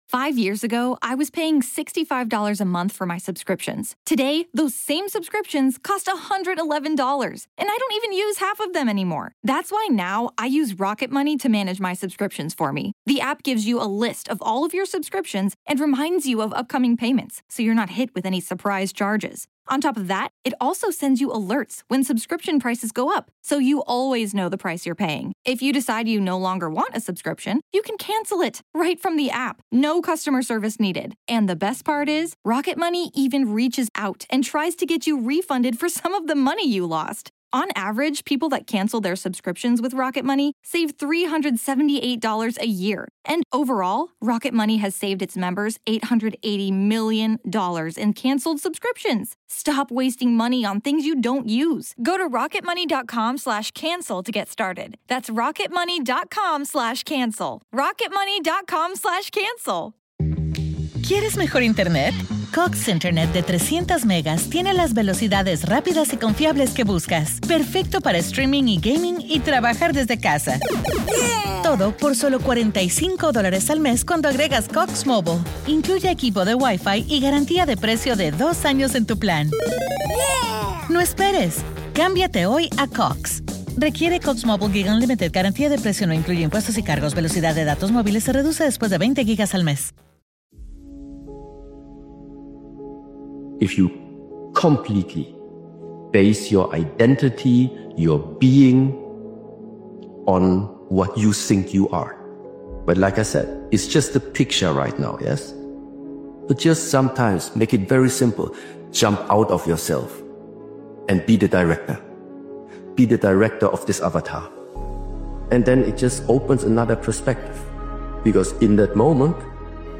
This powerful motivational speech compilation is about reclaiming your momentum after setbacks, doubt, or time lost. A comeback isn’t announced—it’s built through quiet effort, sharper focus, and a mindset that refuses to stay down.